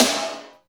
51.04 SNR.wav